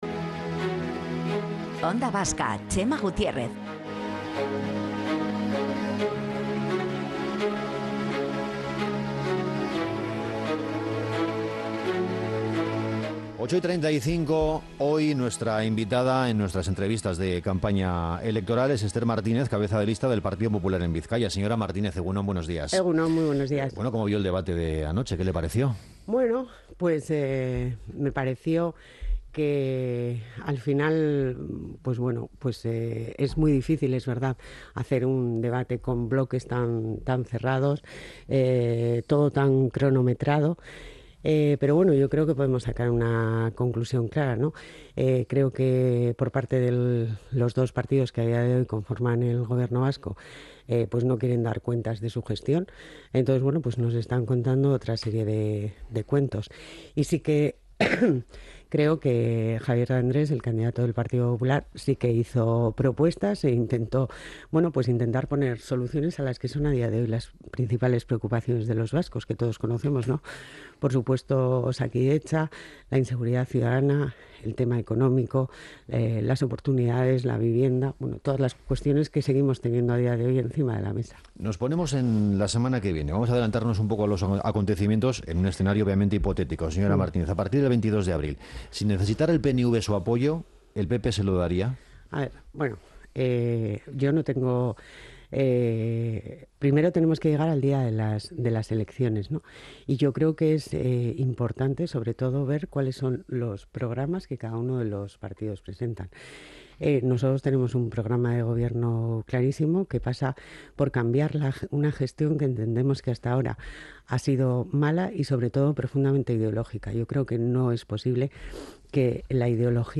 Entrevistas de campaña: Esther Martínez, cabeza de lista por Bizkaia del PP - Onda Vasca